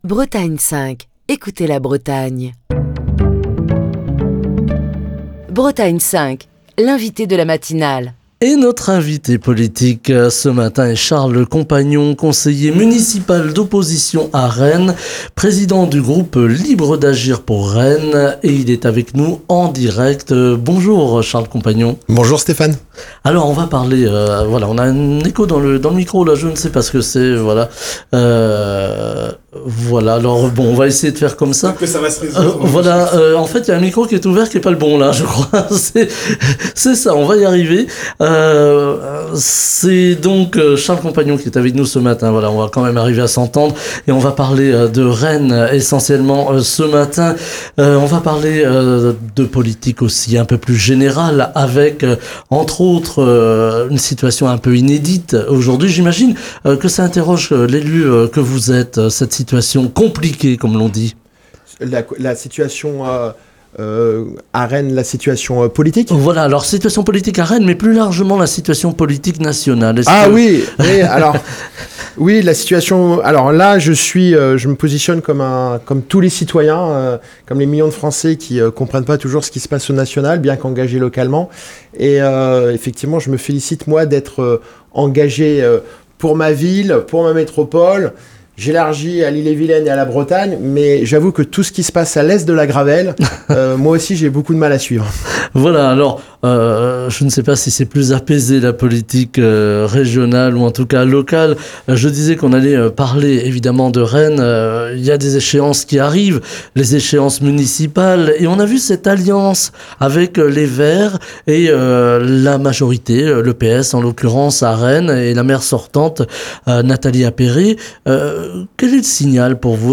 Charles Compagnon, conseiller municipal d'opposition à Rennes, président du groupe Libres d'Agir pour Rennes, était l'invité politique de la matinale de Bretagne 5.